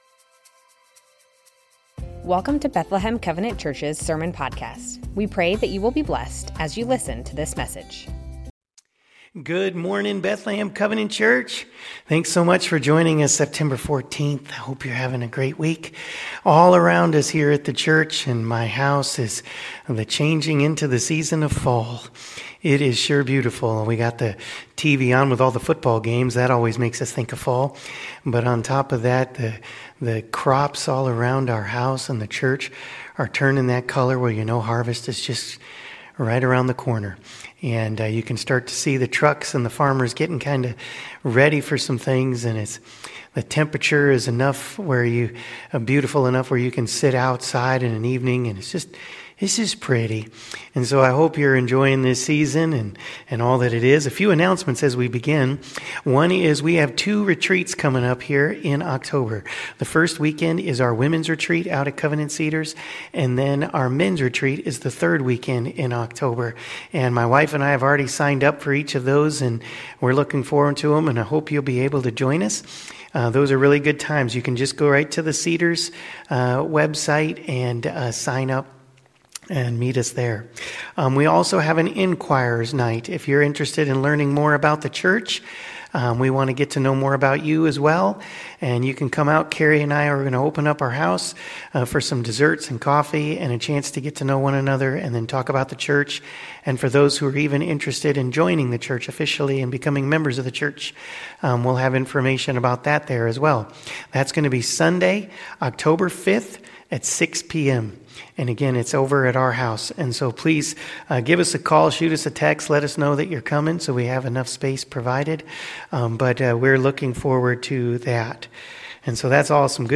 Bethlehem Covenant Church Sermons The Names of God - Jehovah Jireh Sep 14 2025 | 00:32:55 1x 00:00 / 00:32:55 Subscribe Share Spotify RSS Feed Share Link Embed